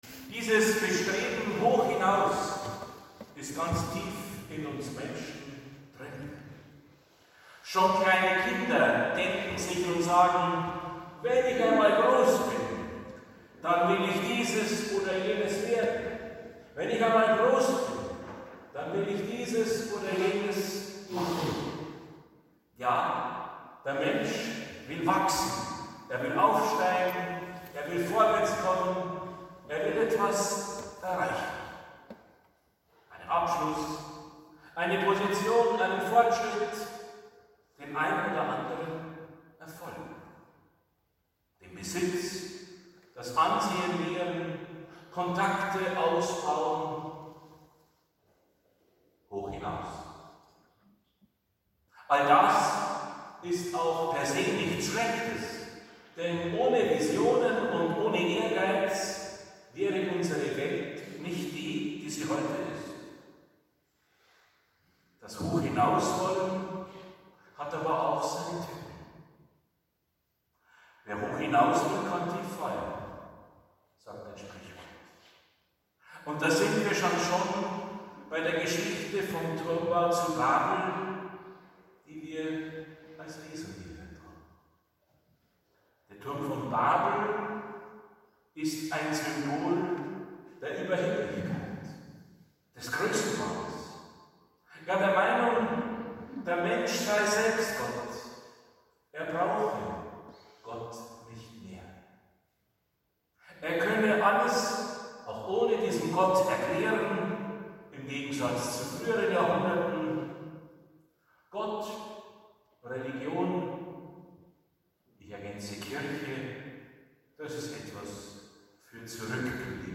Die Predigt
Dekanatsgottesdienst in der Haager Pfarrkirche: "Hoch hinaus"